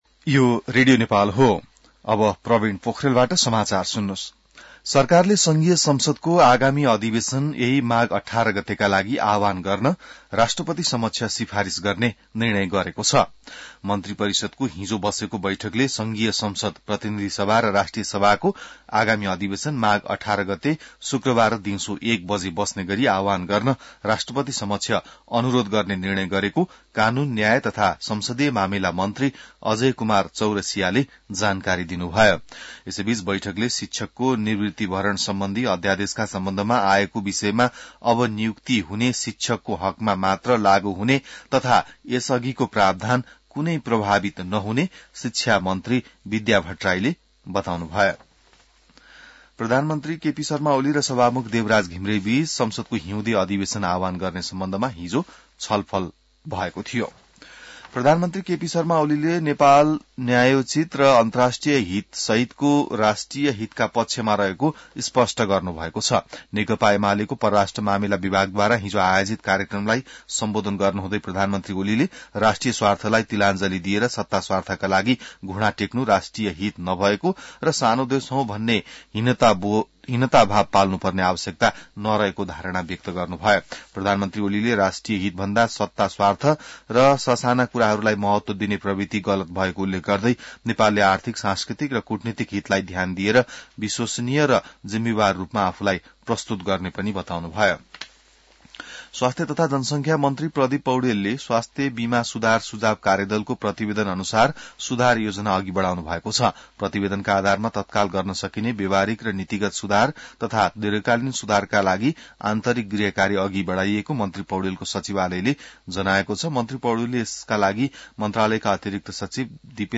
बिहान ६ बजेको नेपाली समाचार : ९ माघ , २०८१